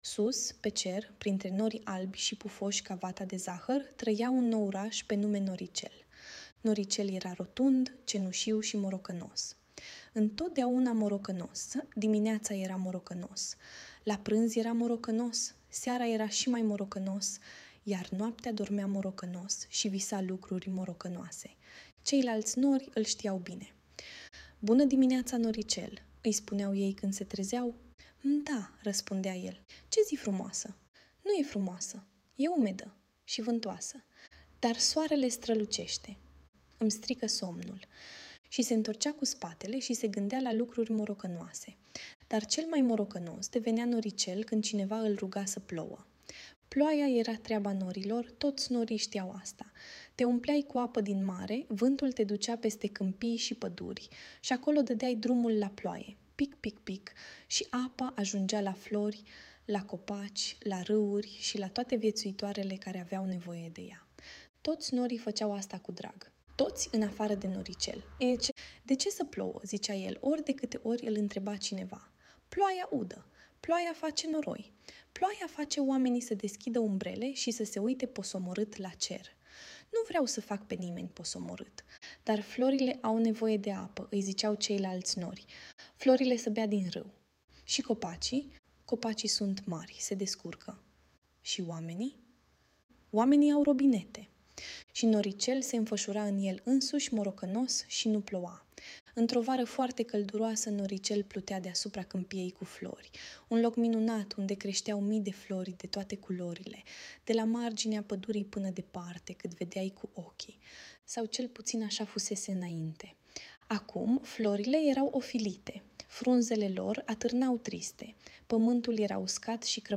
Audiobook Noricel cel morocănos